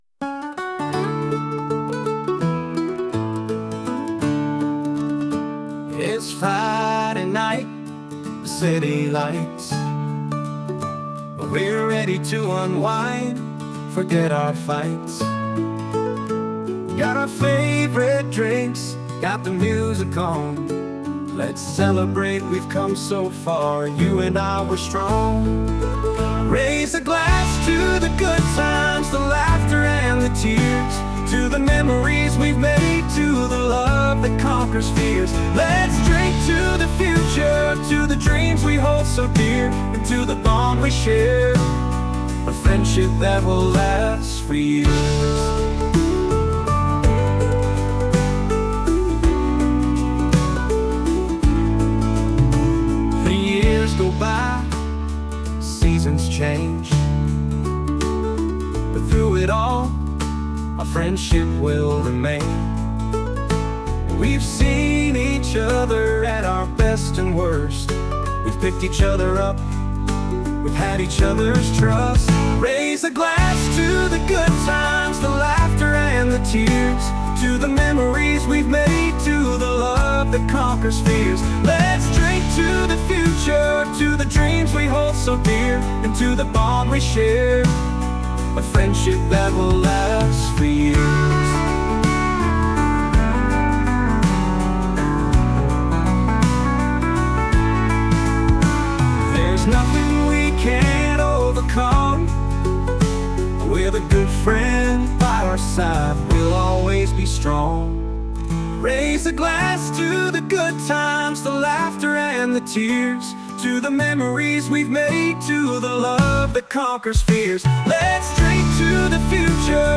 歌詞ありフリー音源。